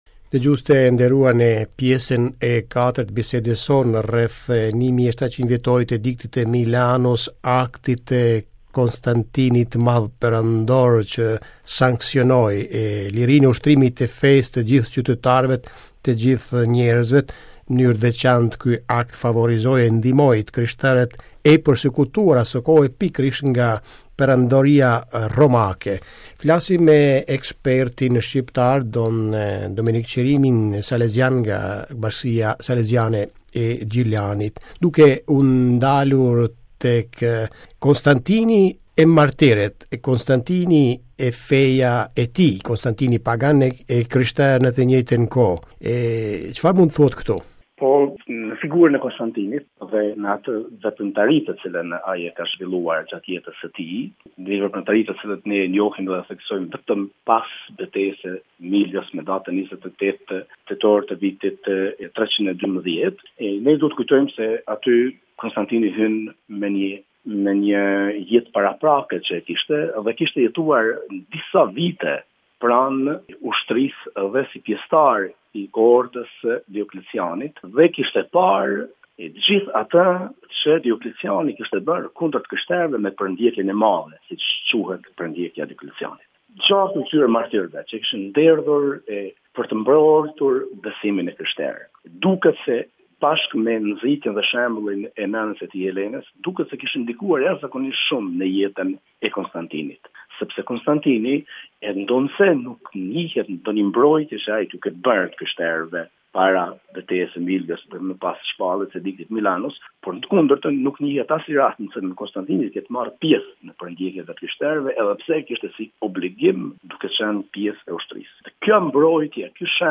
Në vazhdim të bisedës sonë